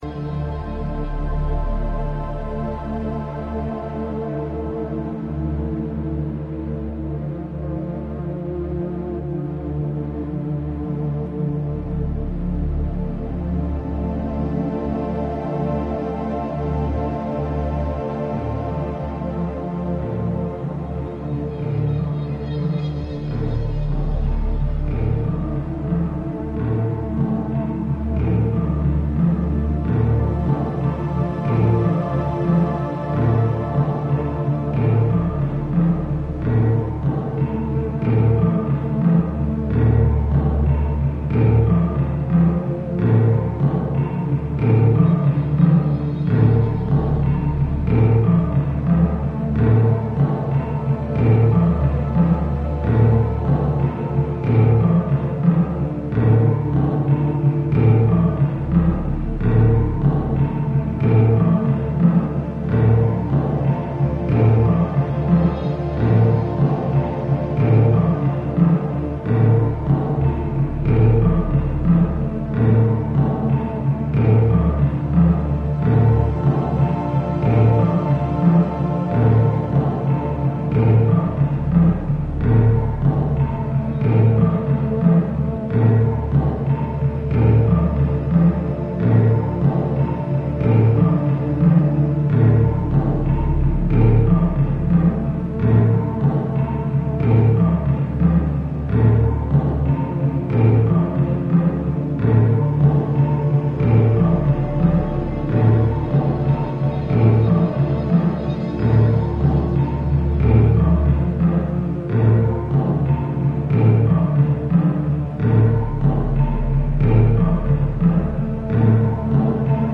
a blend of flutes, percussion, electronics and loops
Electronix Ambient